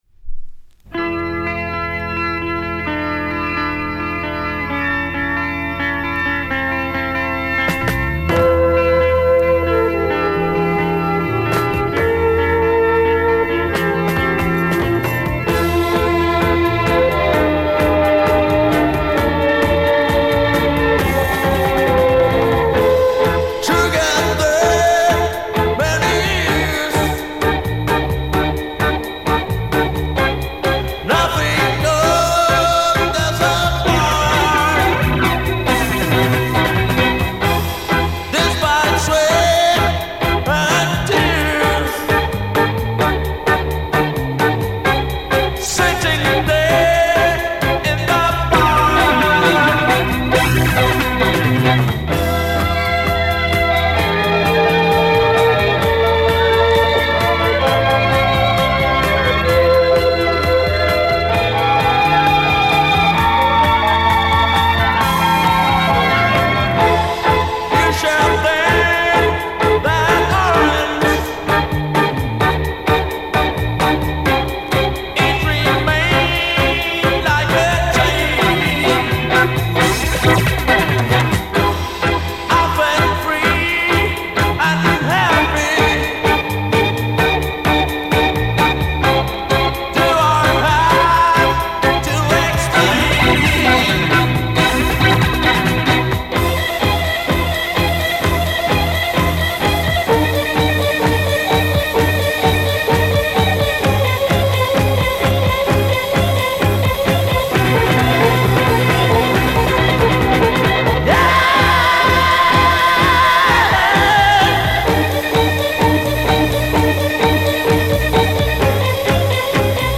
Portuguese Freakbeat Mod 2Siders